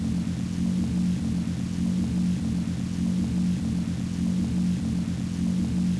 Index of /server/sound/weapons/tfa_cso/laserfist
idle.wav